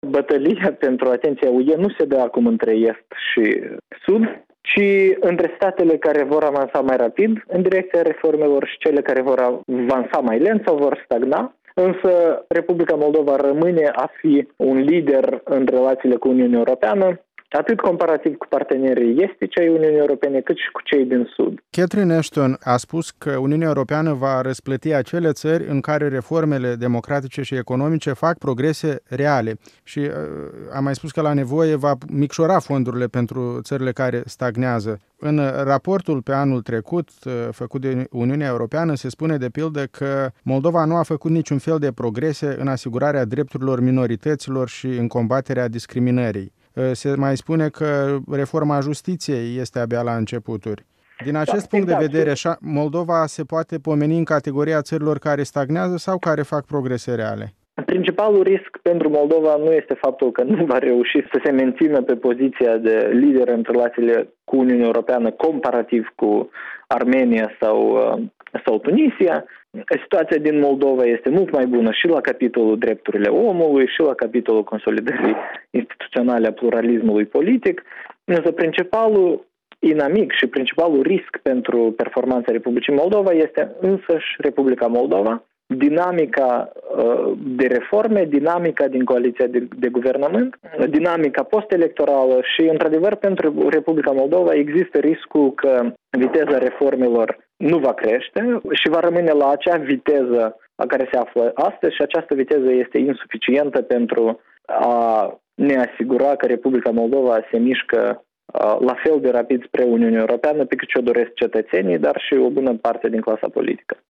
Un interviu cu analistul politic Nicu Popescu